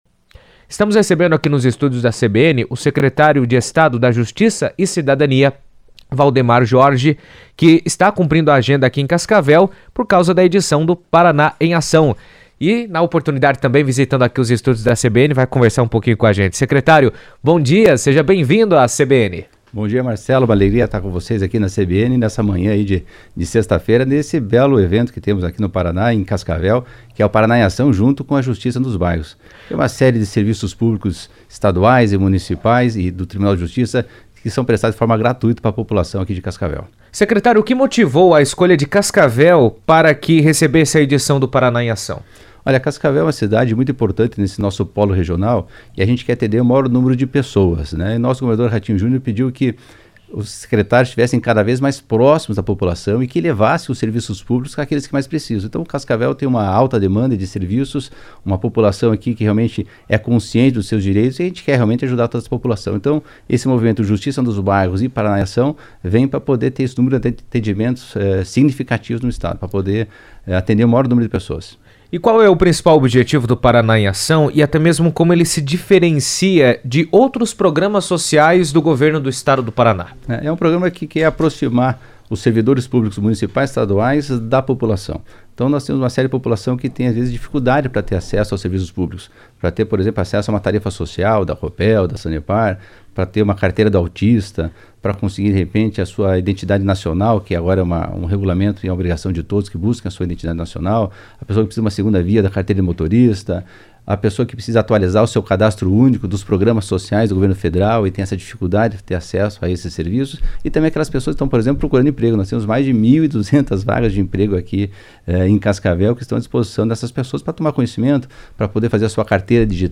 Em entrevista à rádio CBN, o secretário de Estado da Justiça e Cidadania, Valdemar Jorge, destacou a importância do evento para aproximar os serviços públicos da comunidade.